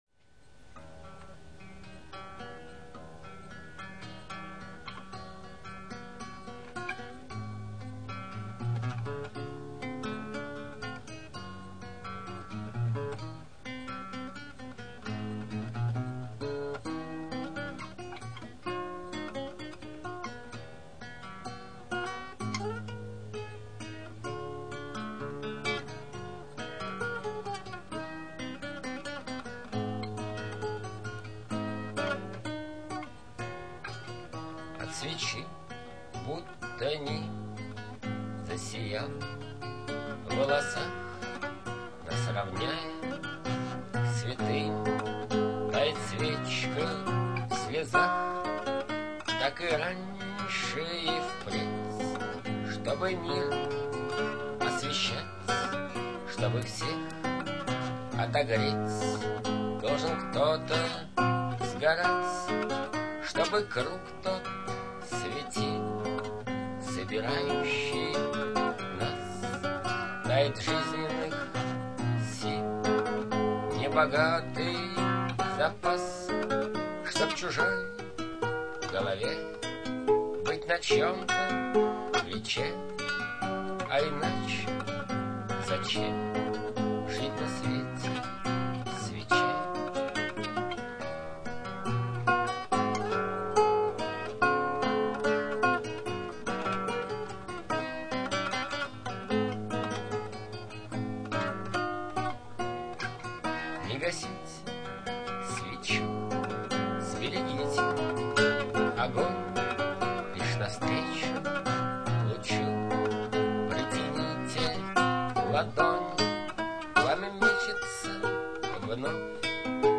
иcполнение (голоc, гитаpа)
22 кГц 16 бит стерео